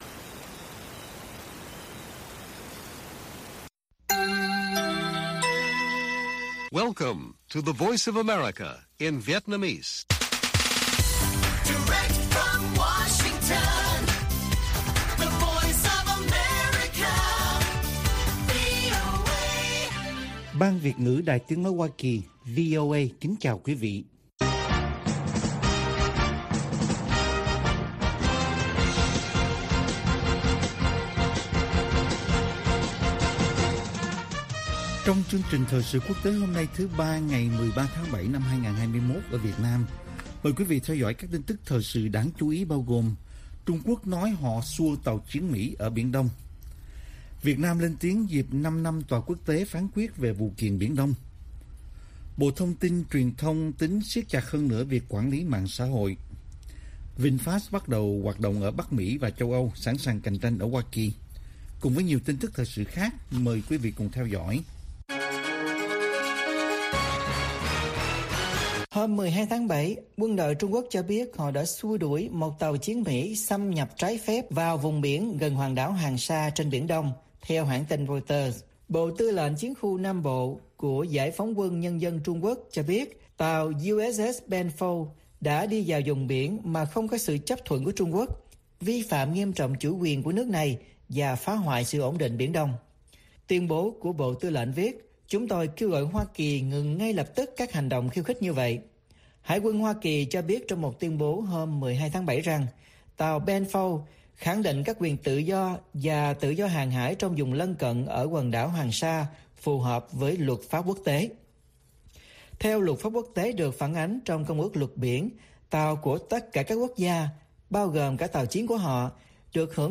Bản tin VOA ngày 13/7/2021